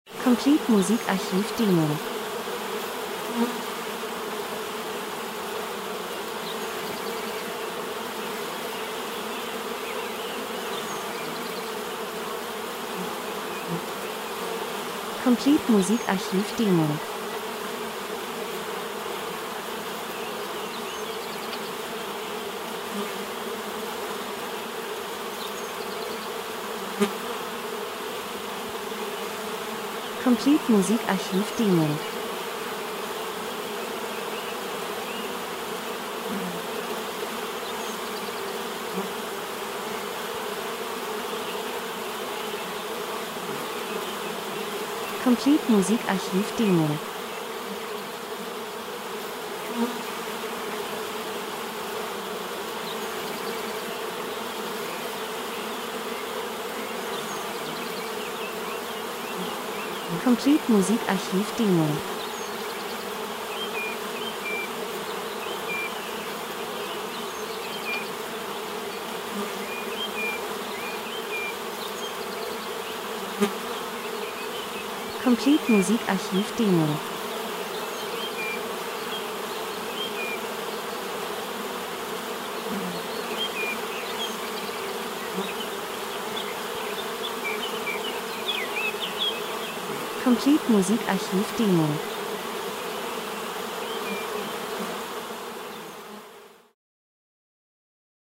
Sommer -Geräusche Soundeffekt Natur Bienenschwarm 01:38